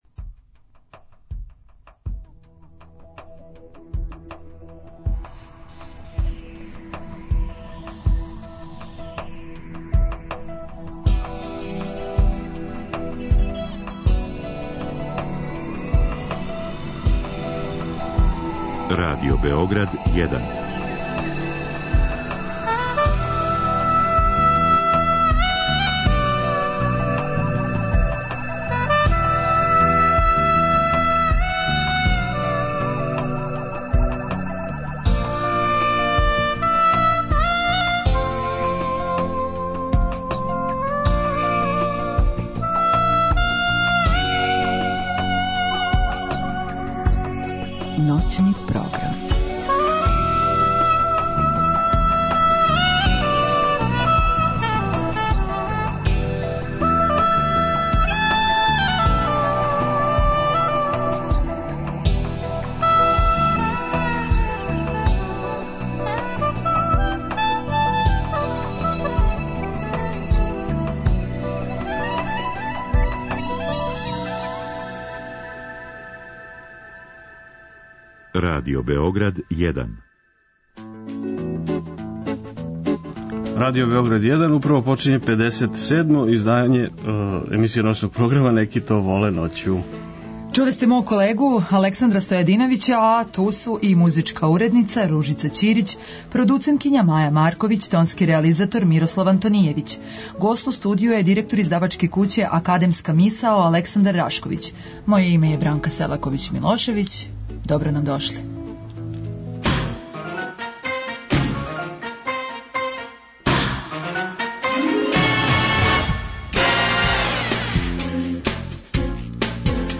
Сваке ноћи, од поноћи до четири ујутру, са слушаоцима ће бити водитељи и гости у студију